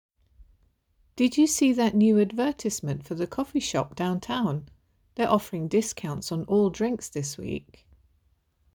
5. GB accent: Advertisement